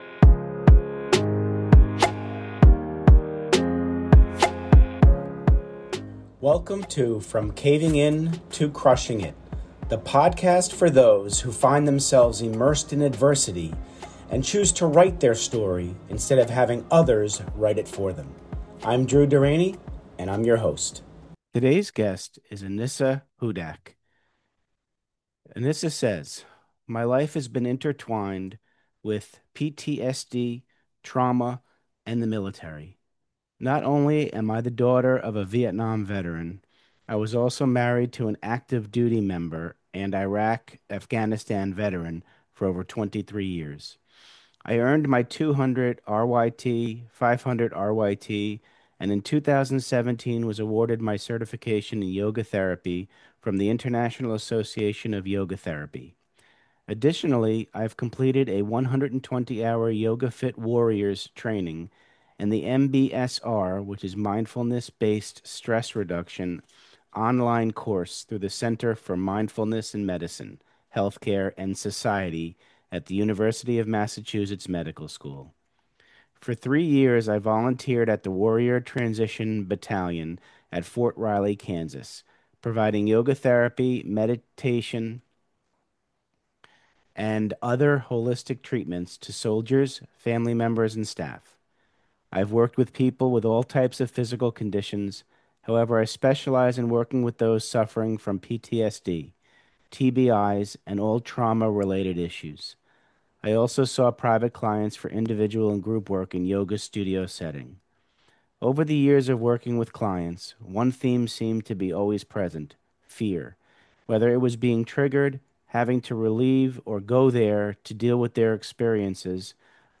In this podcast, I interview men whove been through so much in their lives and have come through stronger.